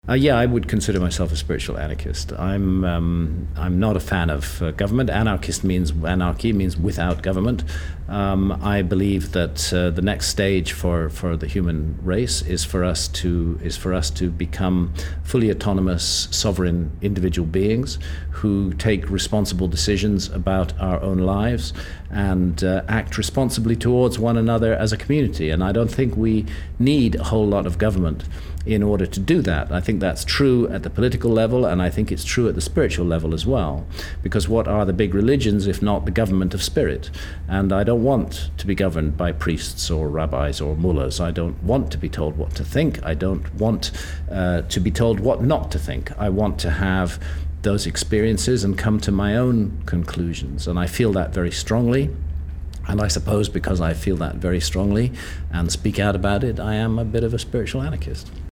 INTERVIEW: Graham Hancock Speaks Out
Then as the chairs were being folded and with only minutes before leaving for the airport, he graciously agreed to sit down with me and field a few questions: